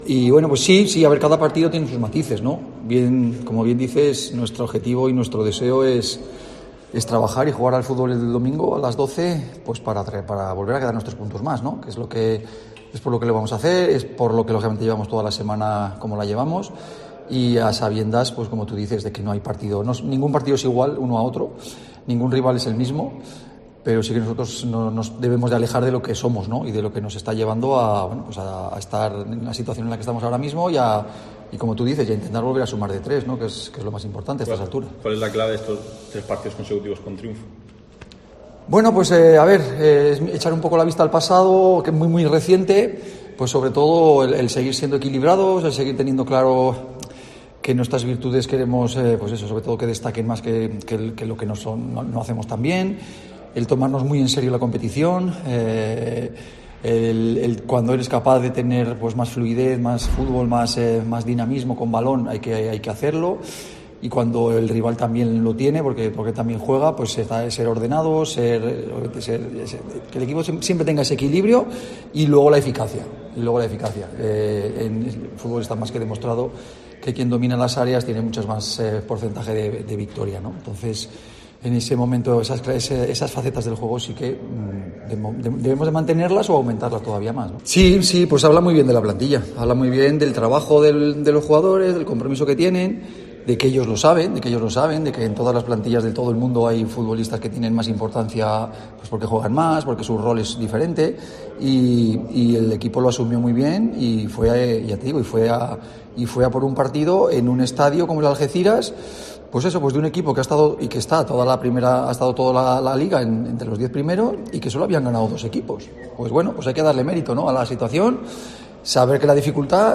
"Debemos seguir siendo un equipo equilibrado y poner nuestras virtudes por delante de las del rival. Aspiramos a ser dinámicos con el balón y ordenados cuando lo tenga el contrario, además de dominar las áreas, pues el que lo hace tiene mucho ganado", comentó Alfaro en la rueda de prensa de este viernes, al tiempo que resaltó "el compromiso de la plantilla" para luchar por el propósito planteado.